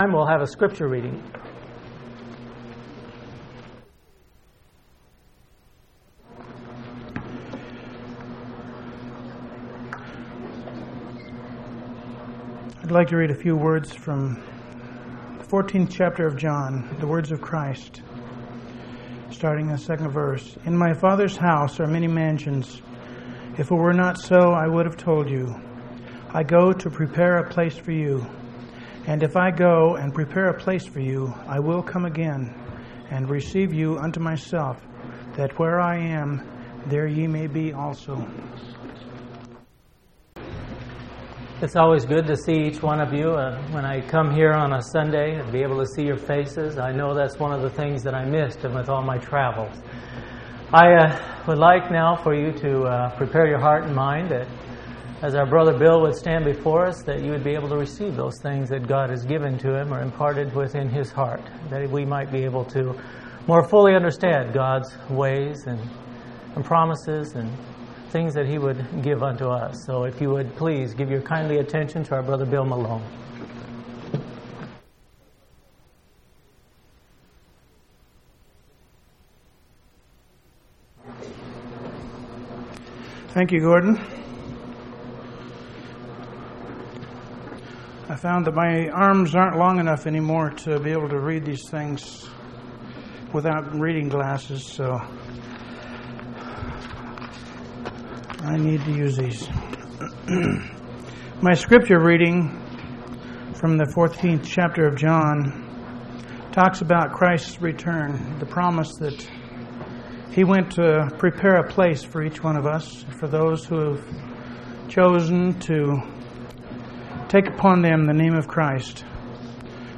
5/28/1995 Location: Phoenix Local Event